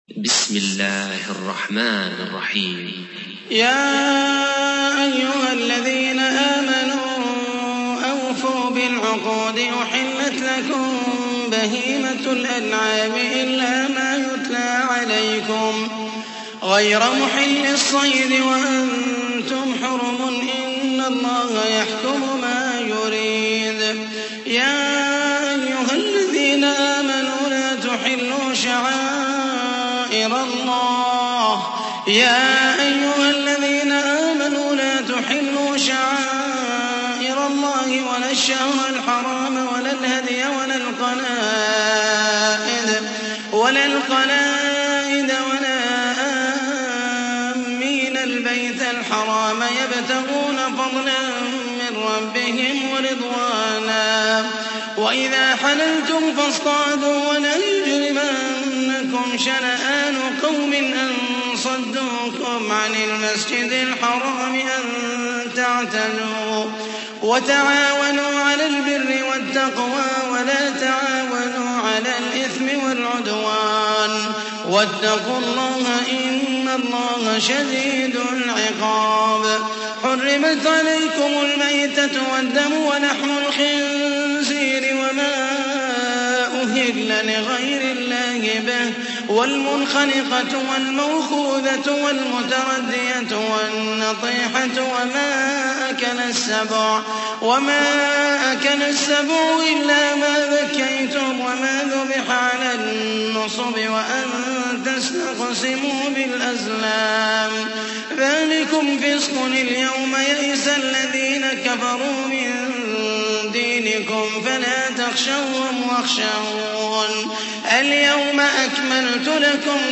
تحميل : 5. سورة المائدة / القارئ محمد المحيسني / القرآن الكريم / موقع يا حسين